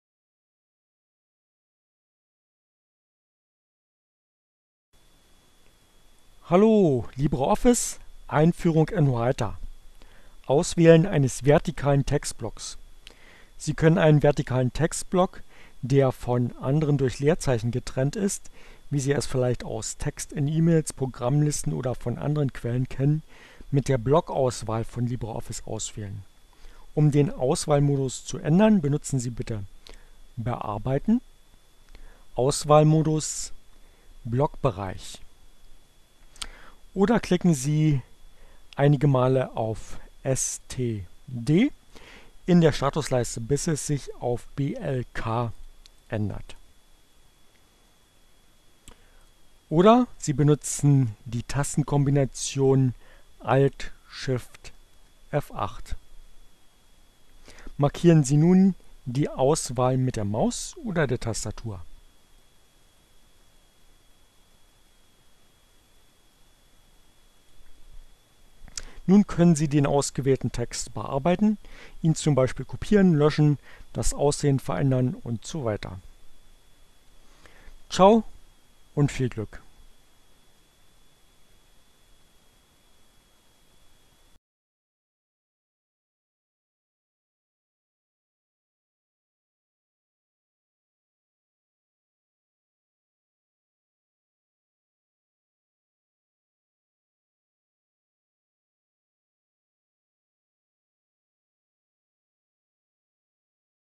Tags: Fedora, Gnome, Linux, Neueinsteiger, Ogg Theora, ohne Musik, screencast, CC by, LibreOffice, gnome3